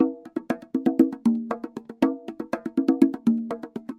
Bongo Pattern
A lively bongo drum pattern with alternating high and low tones in a Latin rhythm
bongo-pattern.mp3